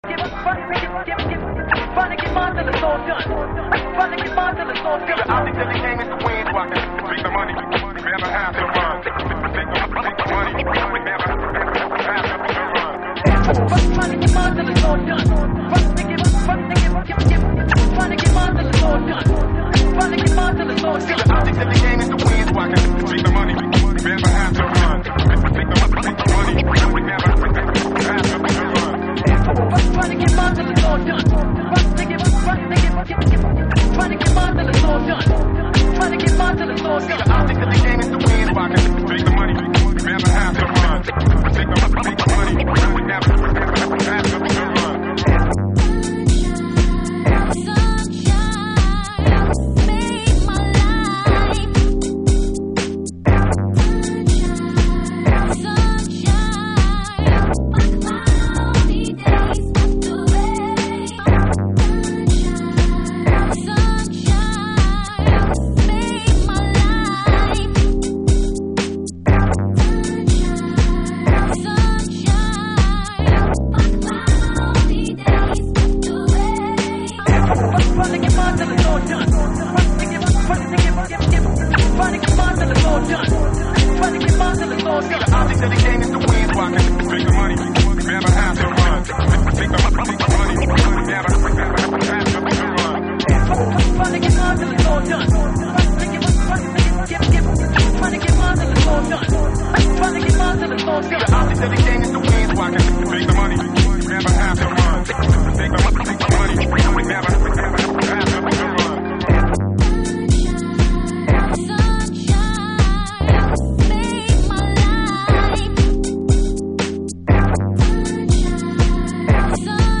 House / Techno
コスってチョップしてますね。メロウなシンセワークとの愛称も良く、セクシーなフロアを演出してくれそうです。